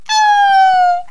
princess_die1.wav